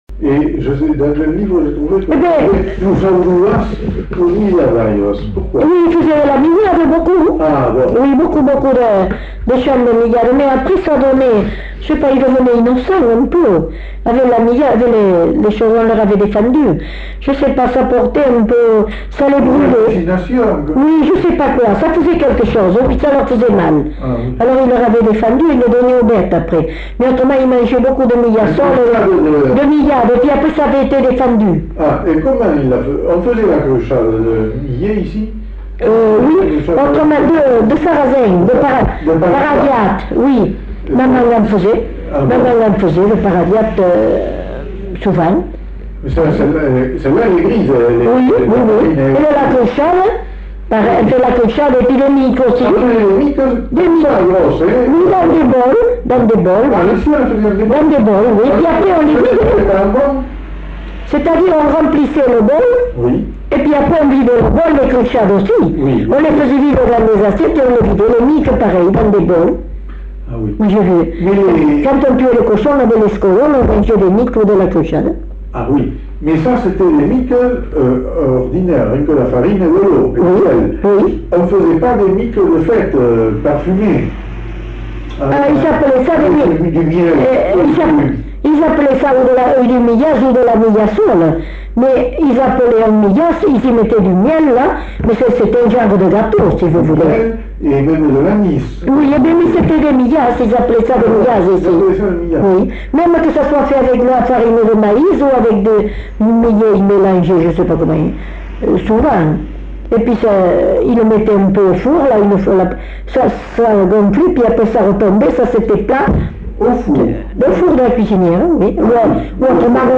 Lieu : Belin-Beliet
Genre : témoignage thématique